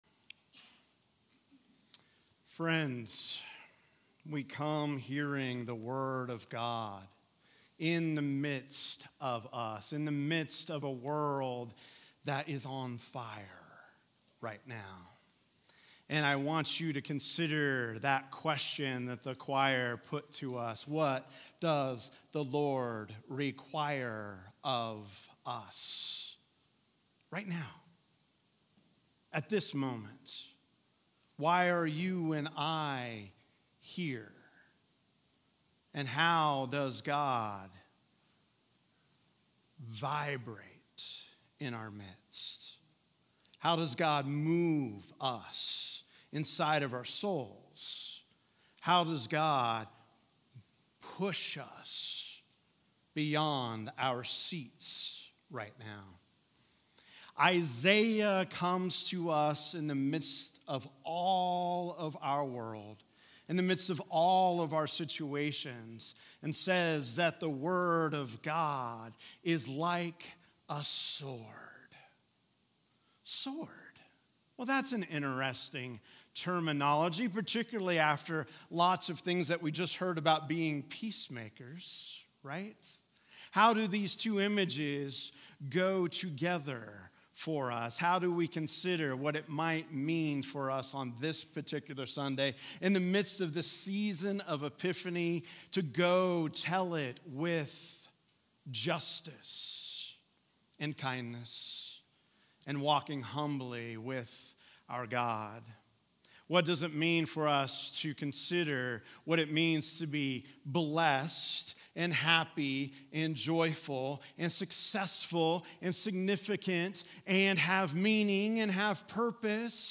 Sermons | Grace Presbyterian Church